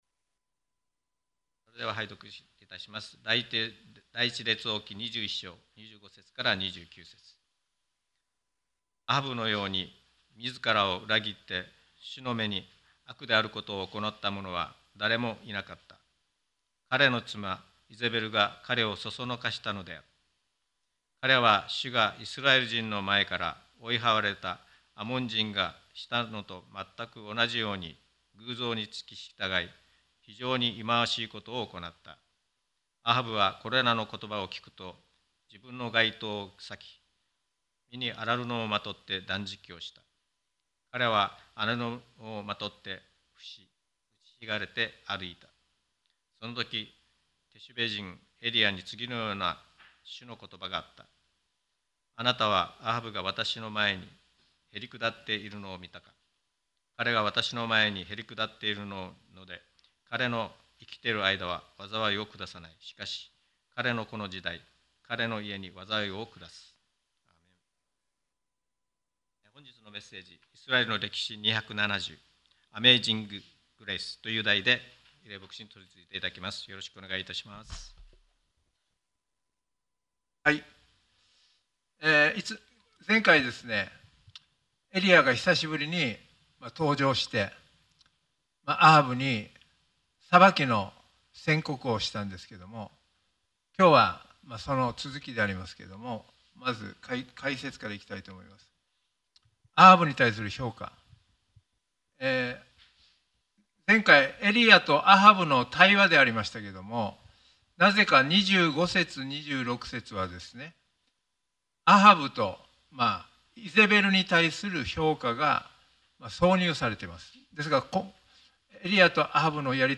沖縄県浦添市にある沖縄バプテスト連盟所属ルア教会です。
2023年12月10日礼拝メッセージ